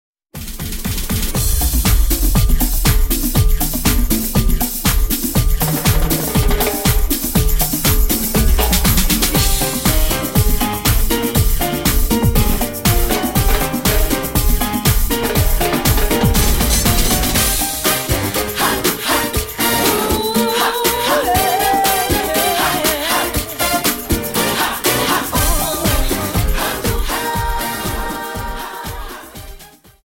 Dance: Cha Cha 31